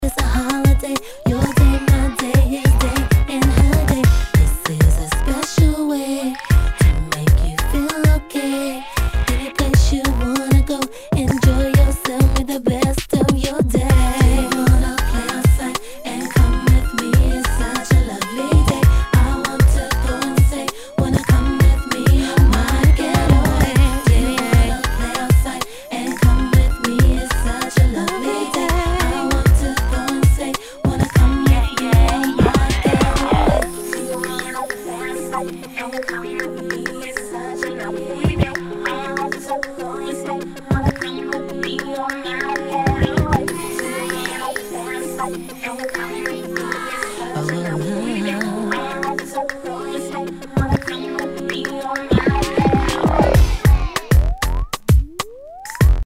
HIPHOP/R&B
ナイス！R&B！